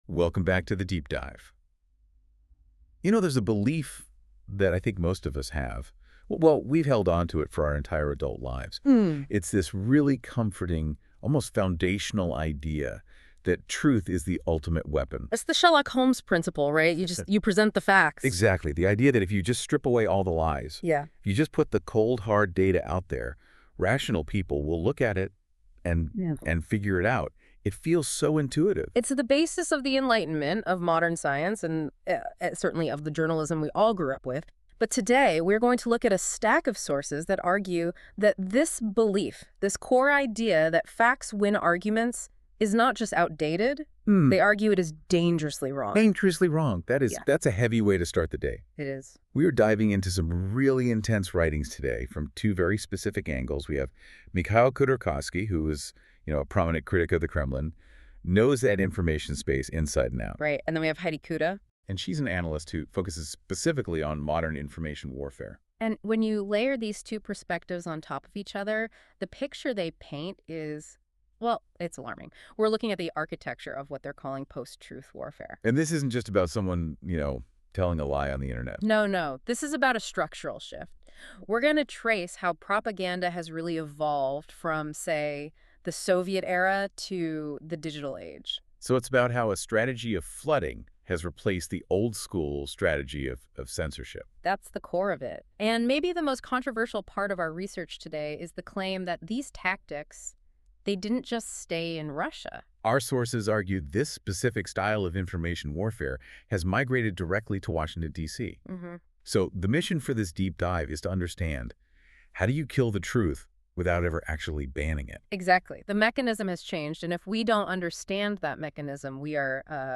It’s been a while, and I’m trying something new…Using Google LLM Notebook, I've generated an audio analysis of specific social media comments.